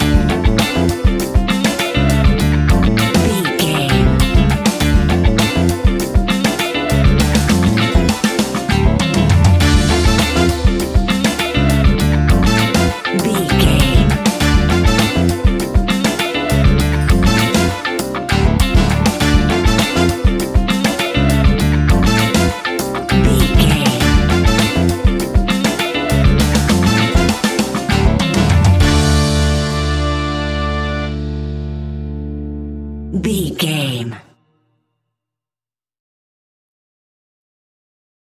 Aeolian/Minor
D
World Music
drums
bass guitar
percussion